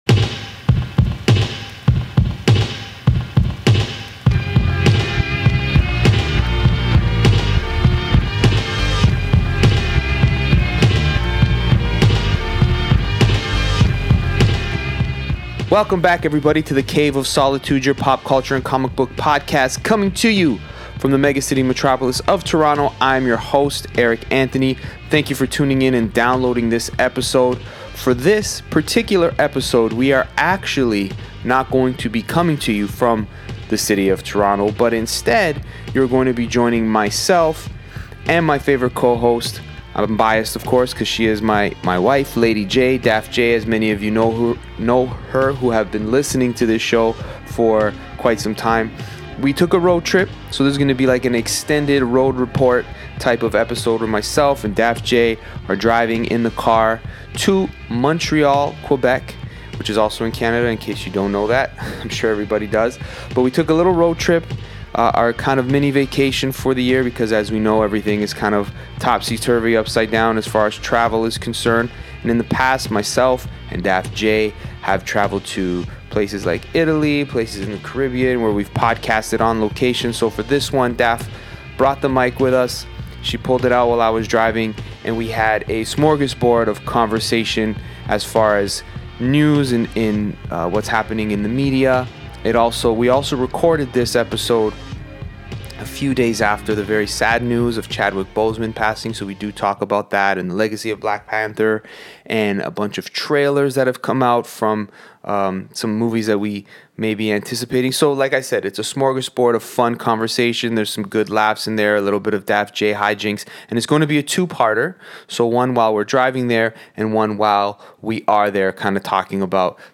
On the way there, they discuss any and everything that comes to mind, but pay special attention to some of the new trailers recently released by DCEU.